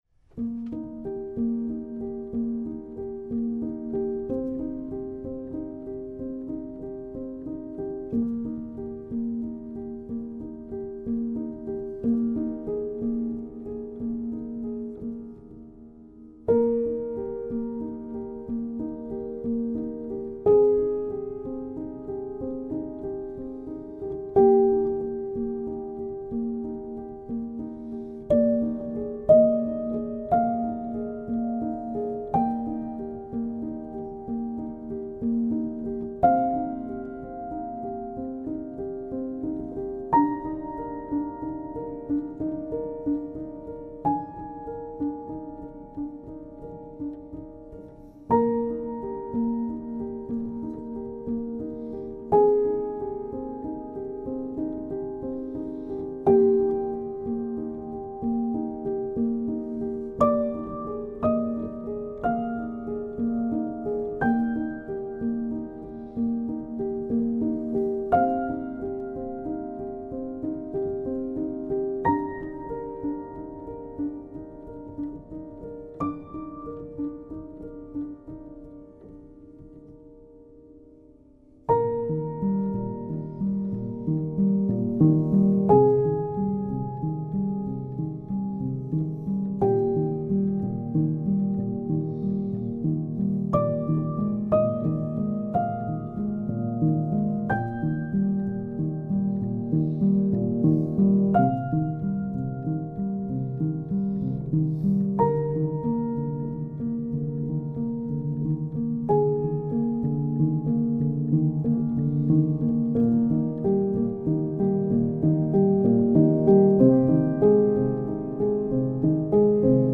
آرامش بخش , پیانو , عصر جدید , موسیقی بی کلام
پیانو آرامبخش